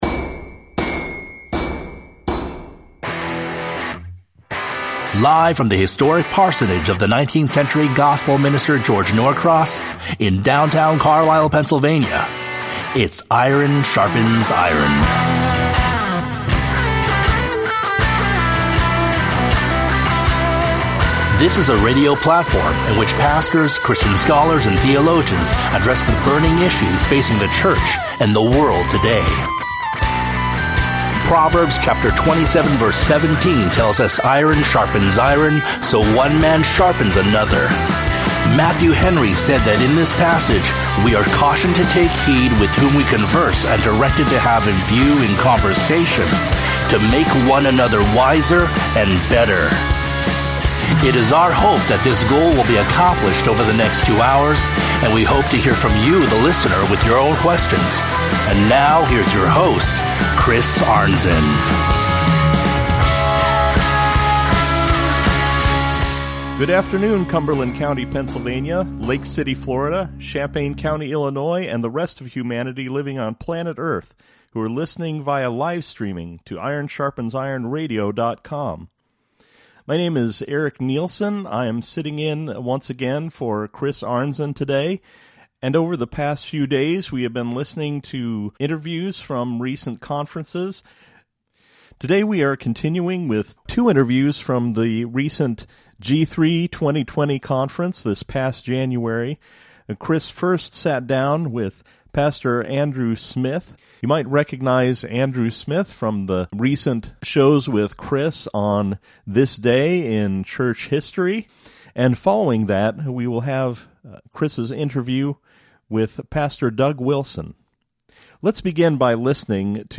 Featuring Interviews from the 2020 G3 Conference with